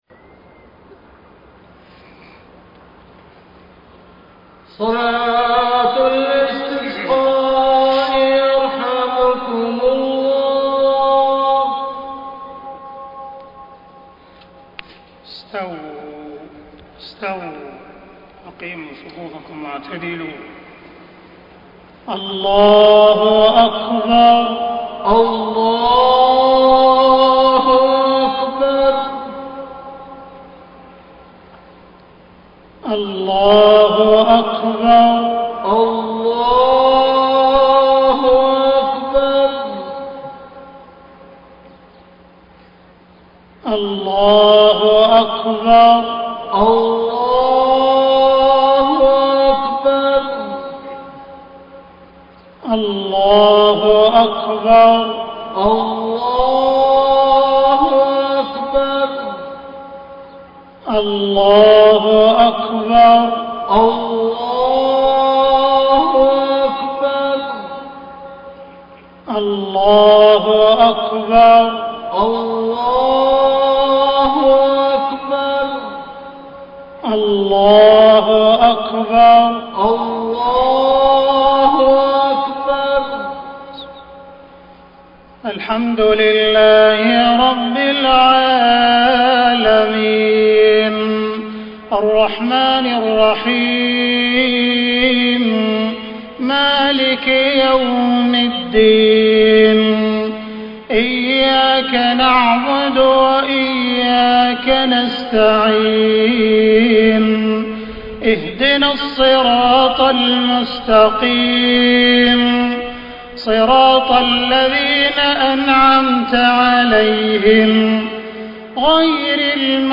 صلاة الإستسقاء 1-2-1433هـ سورتي الأعلى والغاشية > 1433 🕋 > الفروض - تلاوات الحرمين